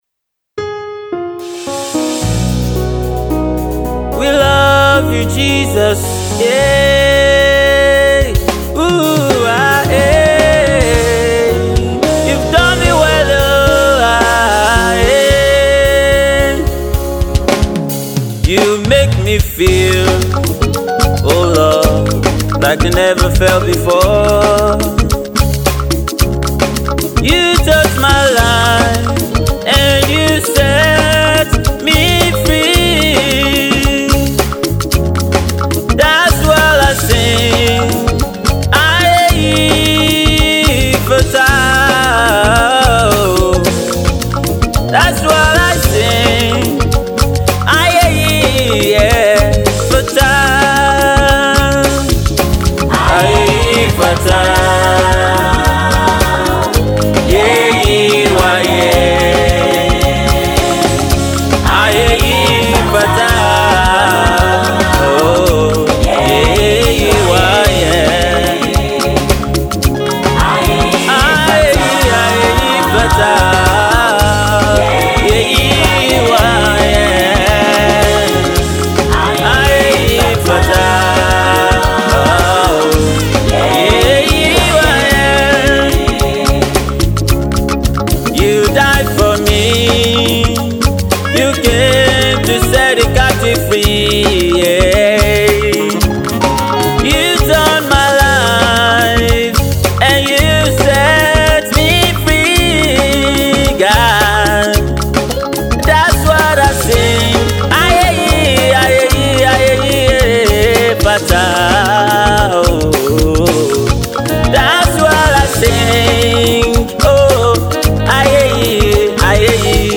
is a power-packed praise jam